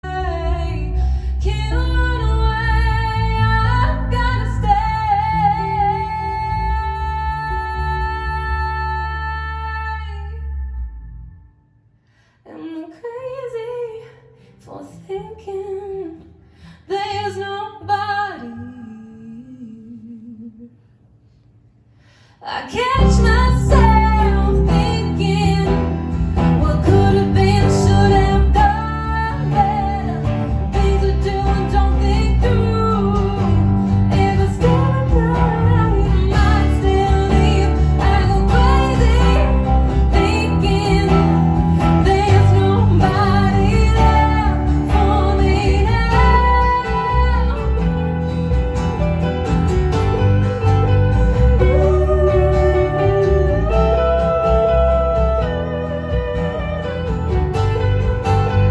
• Pop
• Singer/songwriter
Vokal